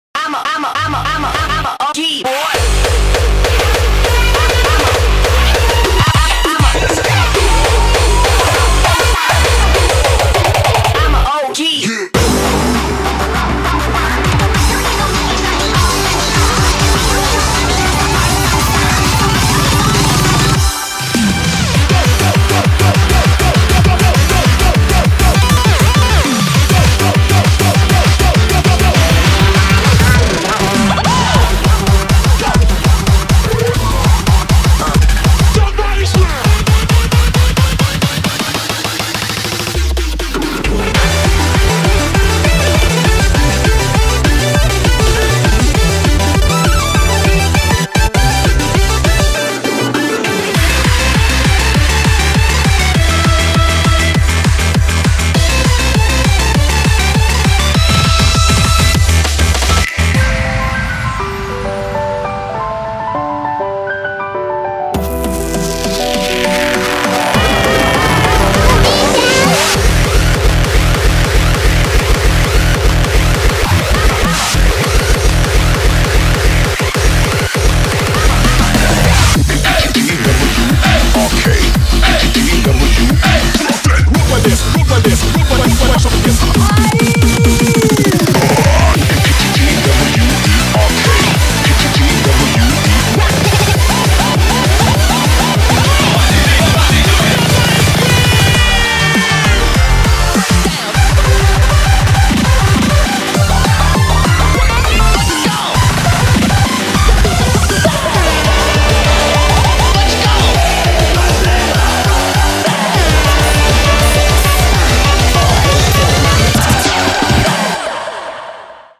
BPM100-400
Audio QualityPerfect (High Quality)
コメント[HARDCORE MEGA MIX]